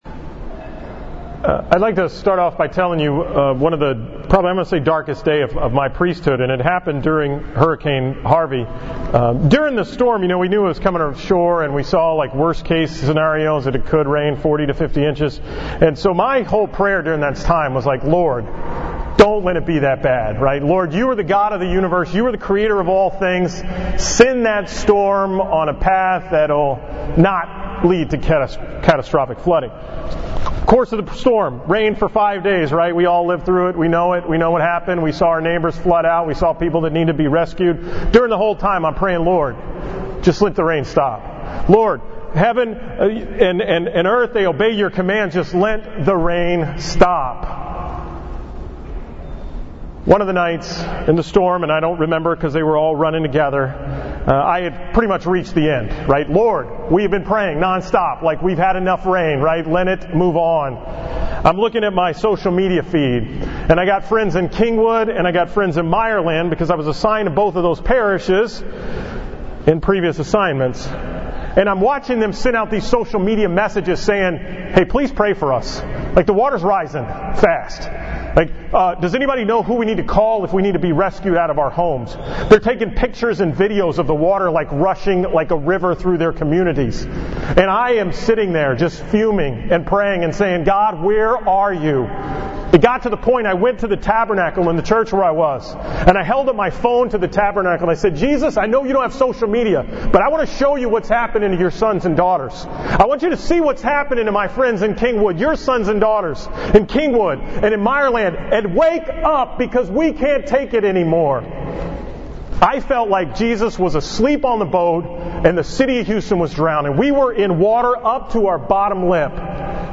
From Mass at Christ the Redeemer on September 10, 2017 on the 23rd Sunday in Ordinary Time.